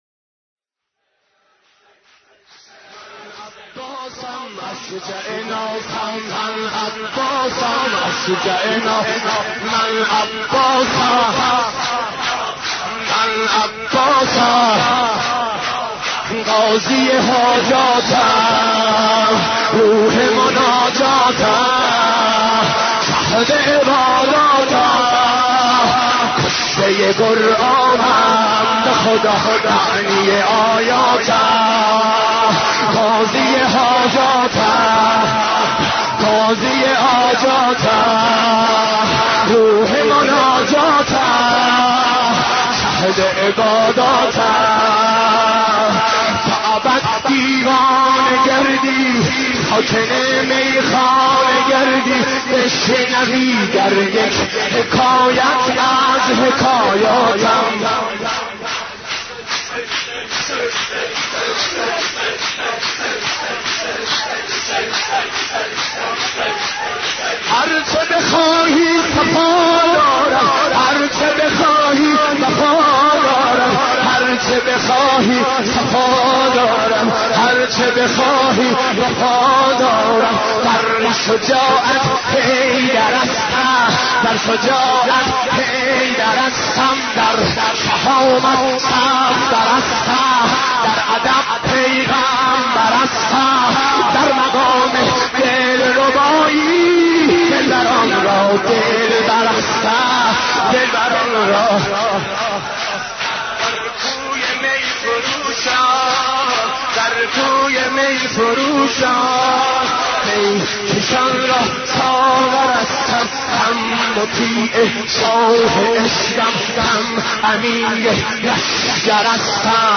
حضرت عباس ع ـ شور 16